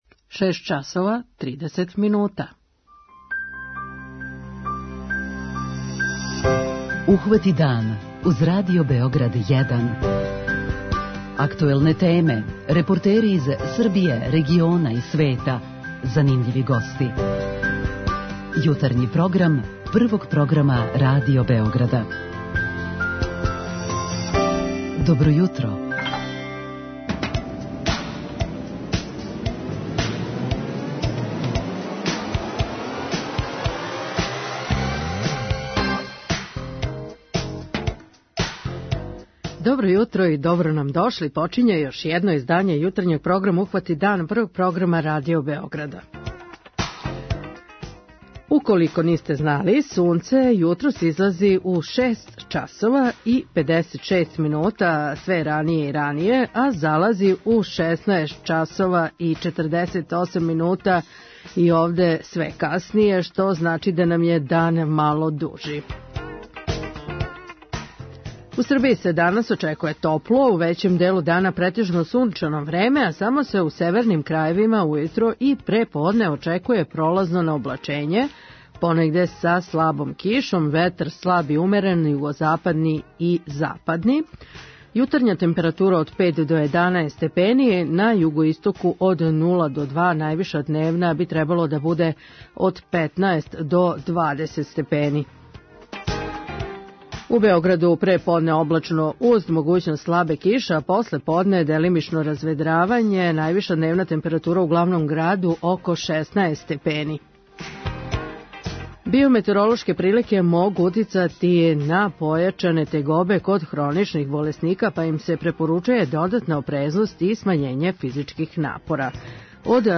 Ово су само неке од тема о којима говоримо овог јутра у нашем јутарњем програму.